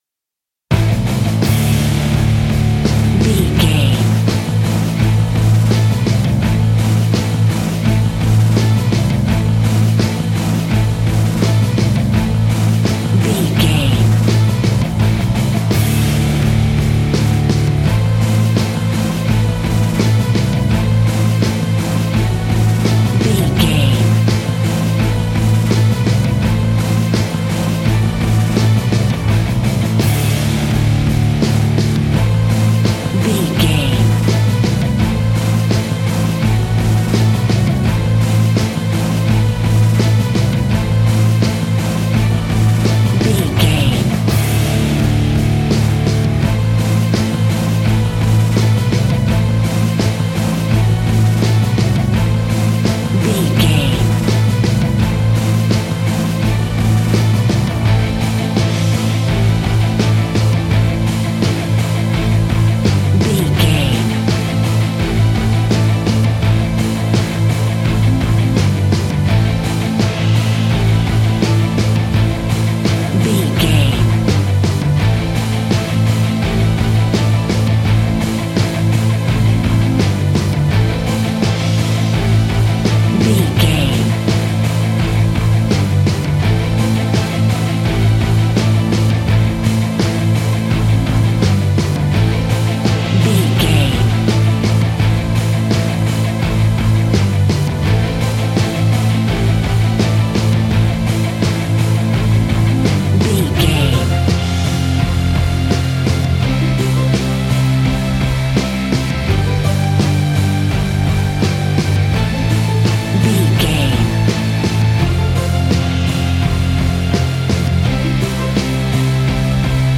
Epic / Action
Aeolian/Minor
Slow
hard rock
heavy metal
horror rock
instrumentals
Heavy Metal Guitars
Metal Drums
Heavy Bass Guitars